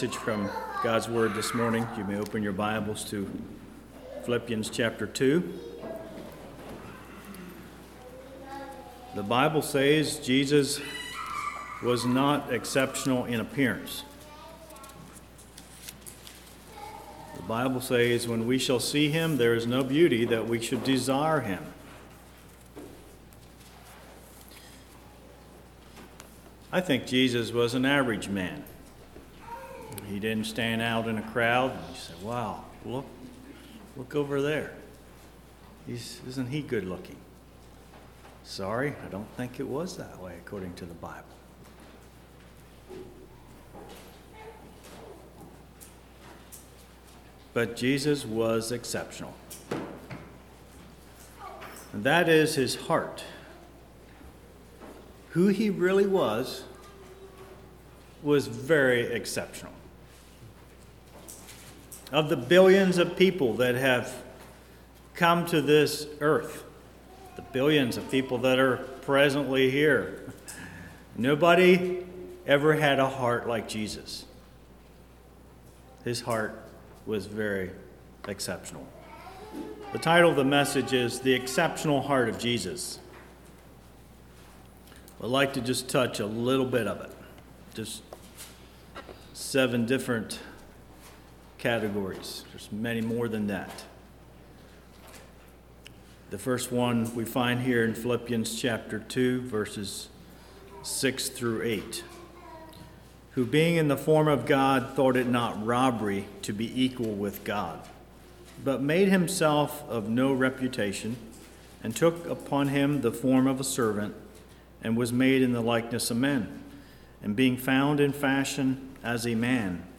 But He did have an exceptional heart. This sermon is a look at some areas or ways that Jesus' heart was exceptional.